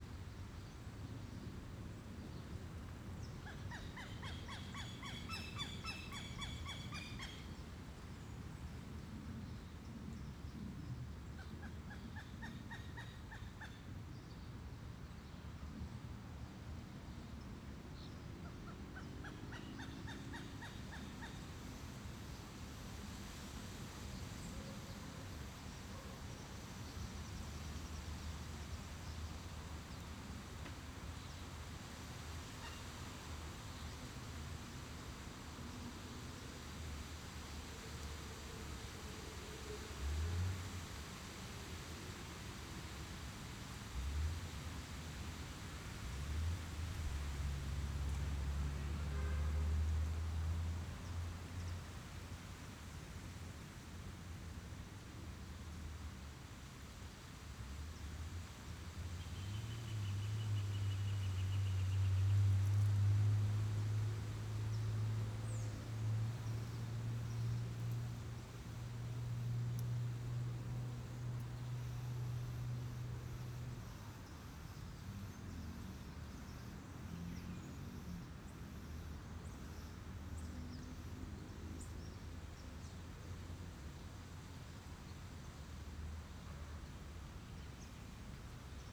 Ambiencia no Parque Ana Lidia com Criancas Brincando na Areia
Crianças brincando
Vozerio criancas Parque da Cidade, Brasília Surround 5.1